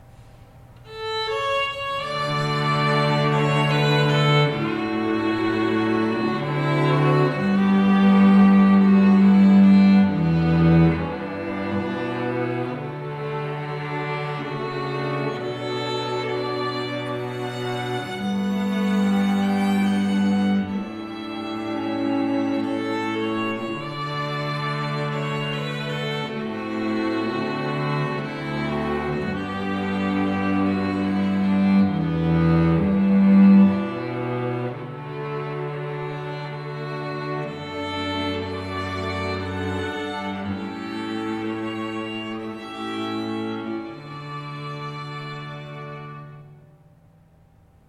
Musical recordings of the quartet - Videos are at the top. Audio files are at the bottom.
Classical